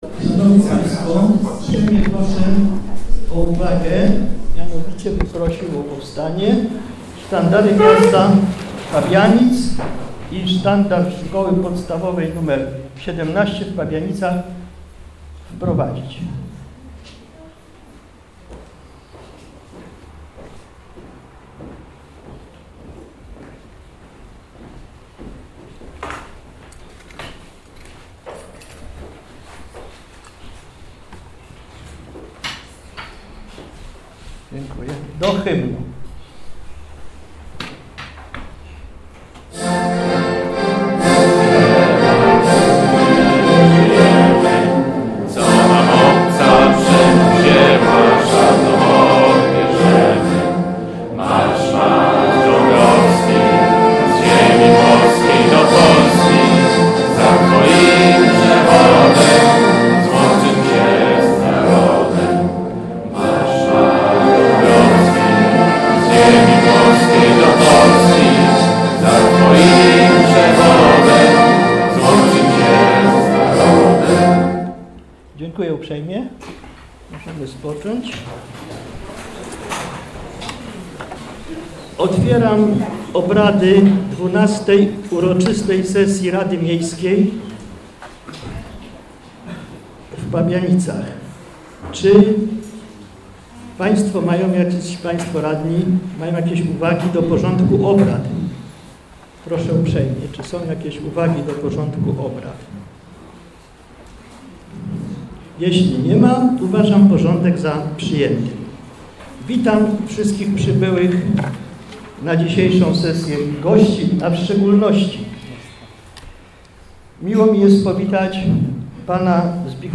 XII sesja Rady Miejskiej w Pabianicach - 12 czerwca 2015 r. - 2015 rok - Biuletyn Informacji Publicznej Urzędu Miejskiego w Pabianicach